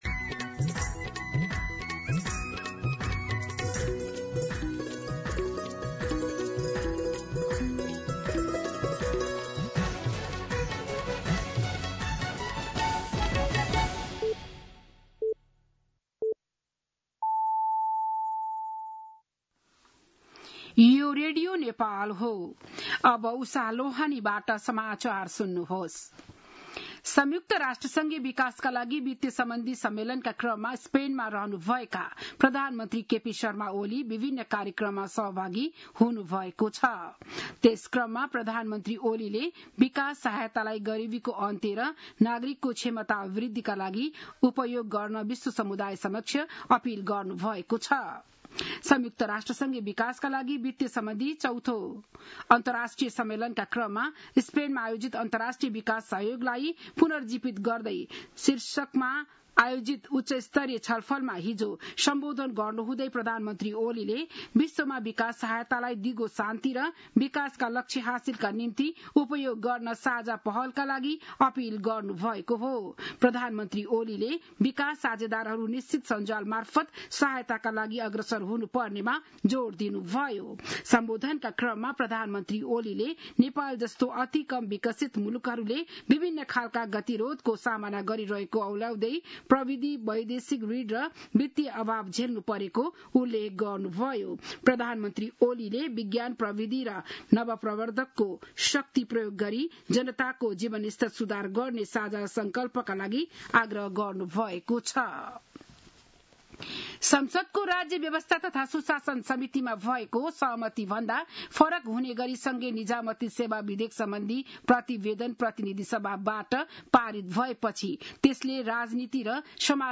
बिहान ११ बजेको नेपाली समाचार : १८ असार , २०८२